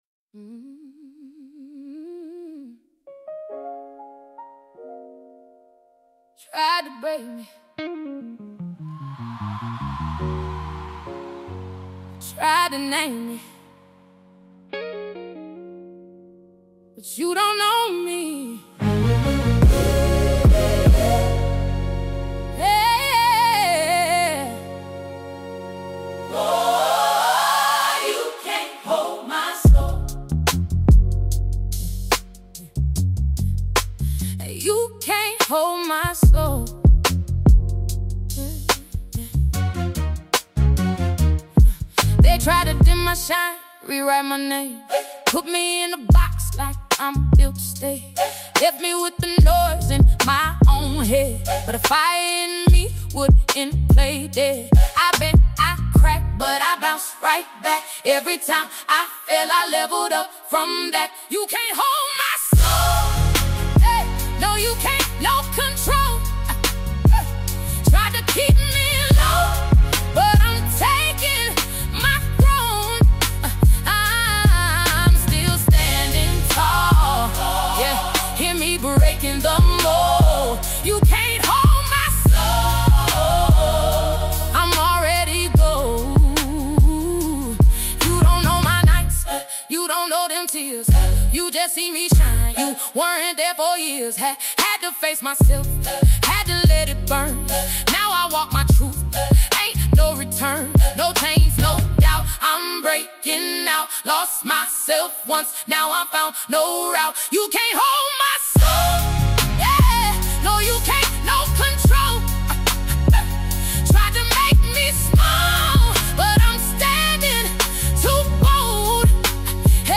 It does not shout, but it is firm.